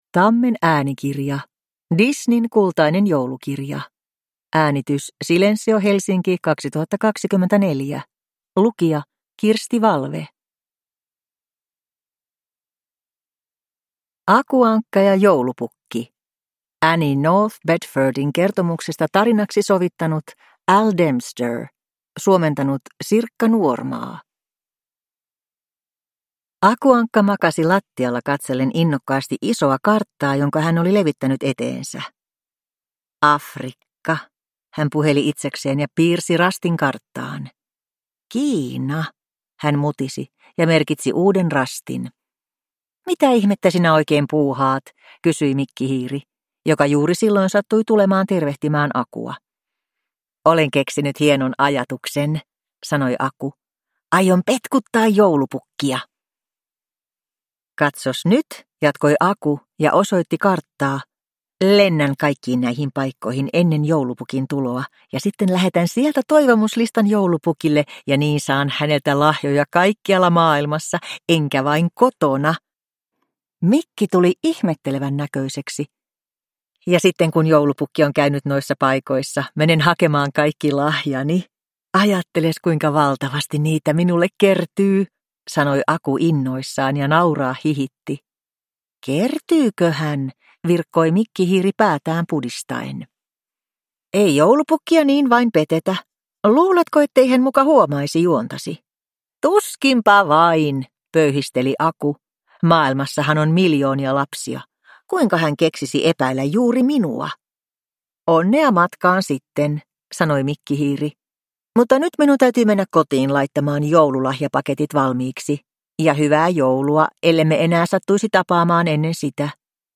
Disneyn kultainen joulukirja – Ljudbok